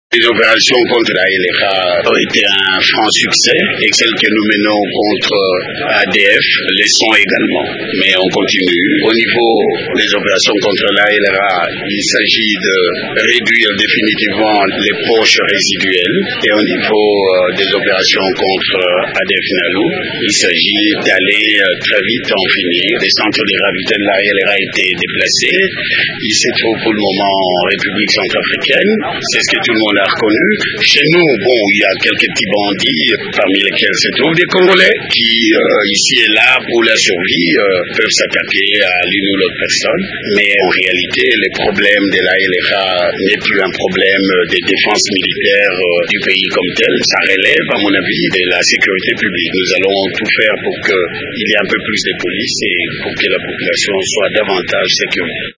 Le chef d’état-major général des FARDC parle du bilan de ces opérations à Radio Okapi: